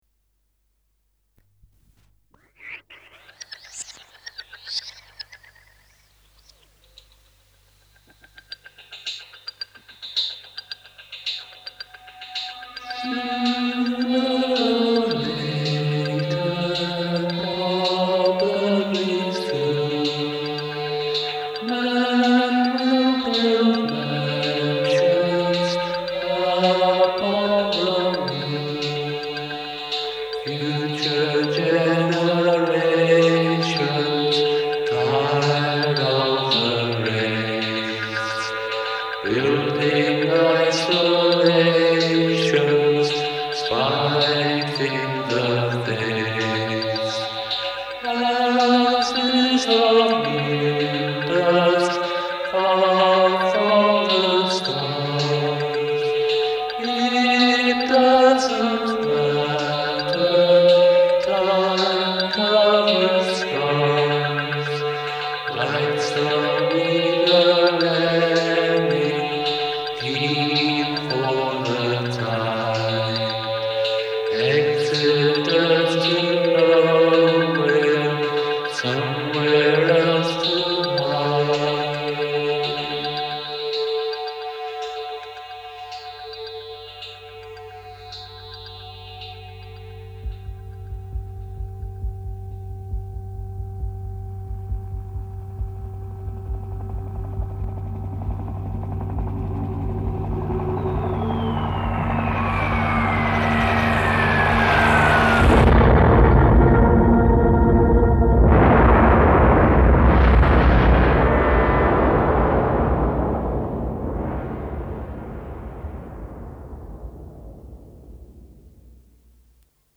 stereo audio